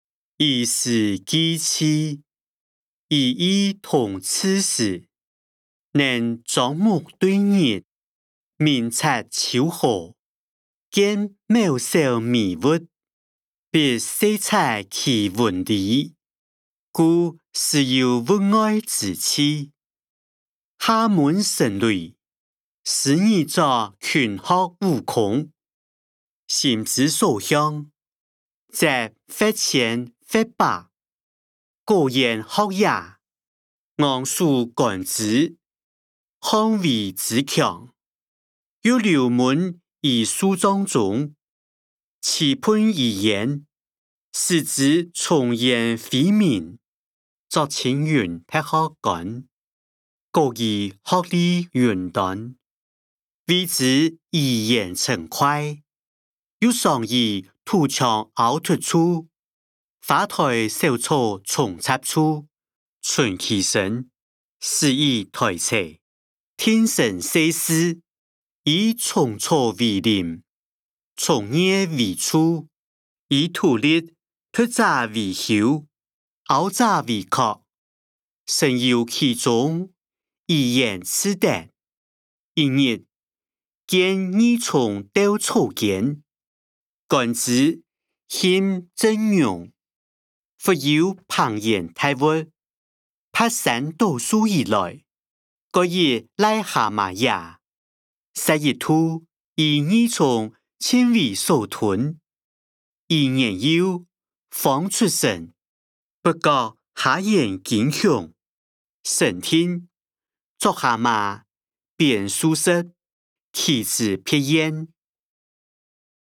歷代散文-兒時記趣音檔(四縣腔)